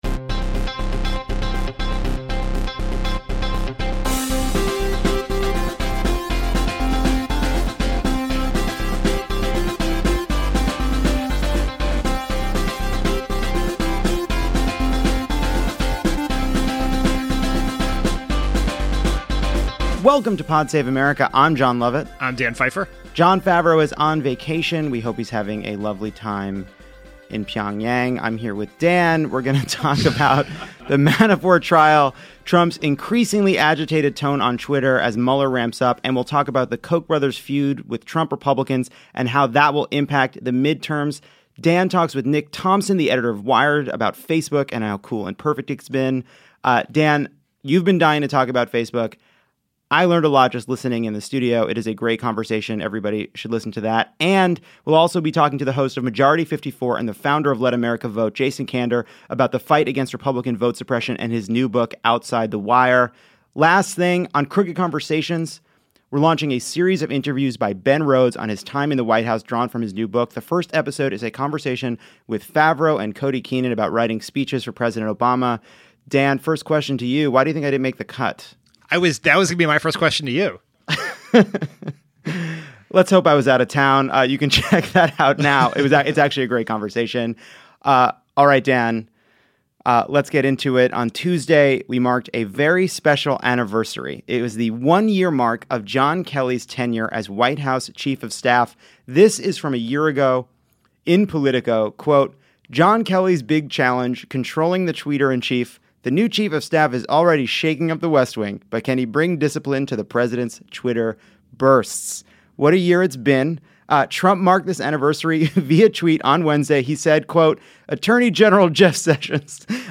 Trump obstructs justice via Twitter, Manafort faces a jury, and the Koch brothers pick a fight with Trump Republicans on trade. Then Dan talks to Nick Thompson of Wired about the fantastic job Facebook has been doing lately. And Jason Kander joins Lovett and Dan to talk about protecting the vote and his new book, “Outside the Wire.”